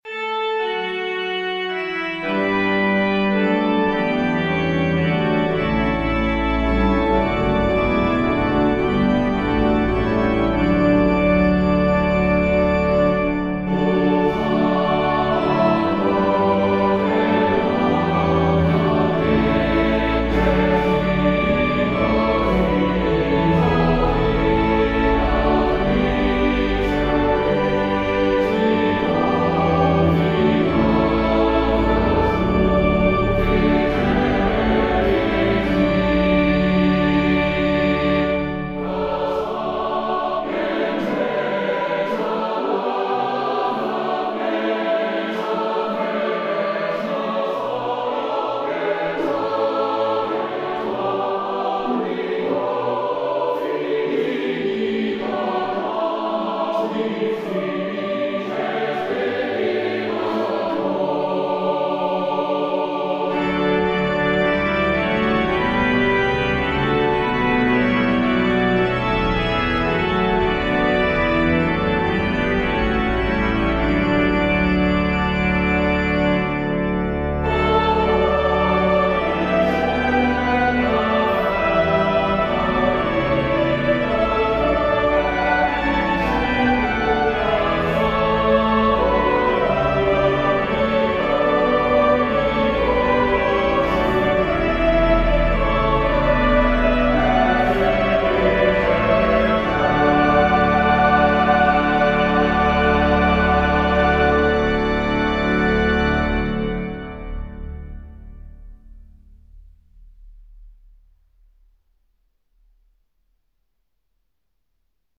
Harmonized descant to the hymn tune BEDFORD. This is for the EDMONTON variant of the original triple meter setting, and also includes our own SATB harmonization